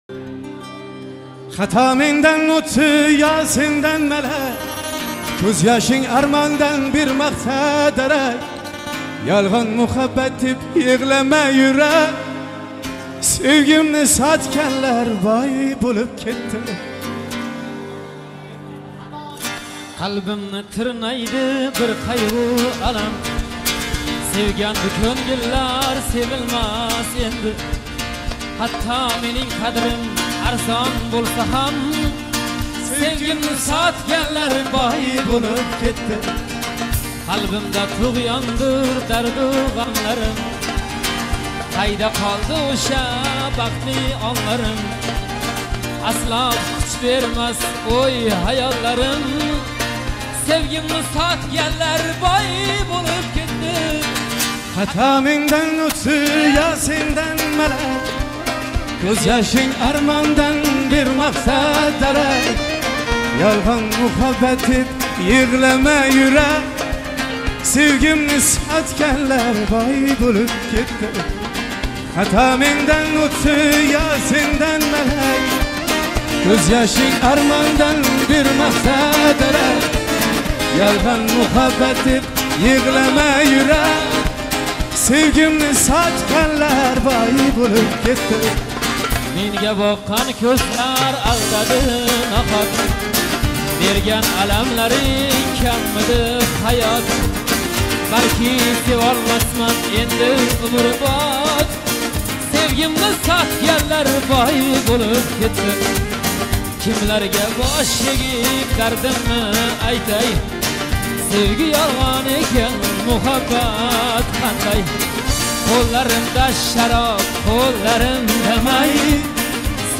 jonli ijro